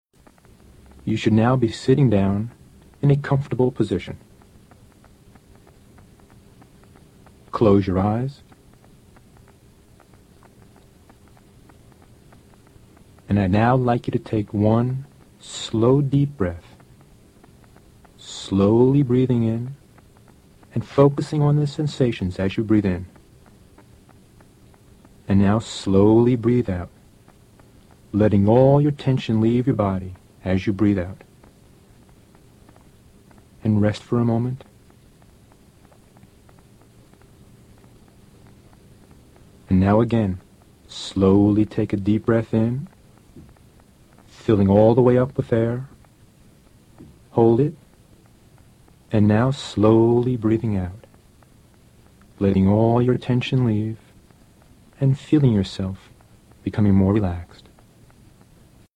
i Cope Breathing Exercise This is a one-minute breathing exercise where you learn to take deep breaths, hold that breath for a few seconds and then slowly exhale. This does not result in deep relaxation but is part of the daily relaxation steps used in the i Cope procedure when you need a quick reduction of your stress level.
one-minute-relaxation.mp3